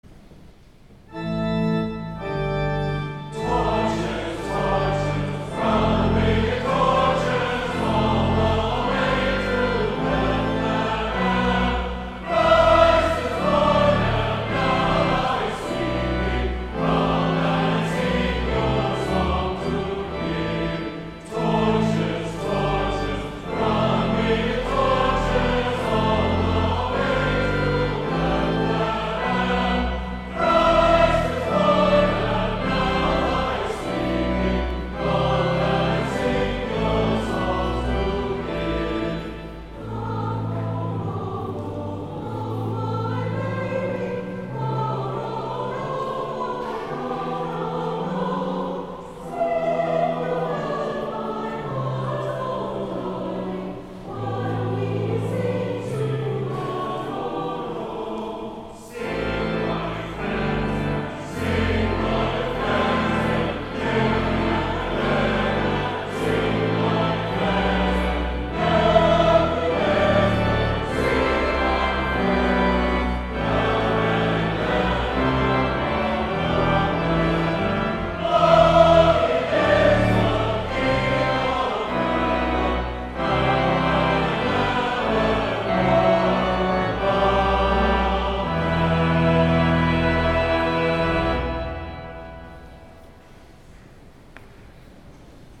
Christmas Eve
Holy Eucharist
Cathedral Choir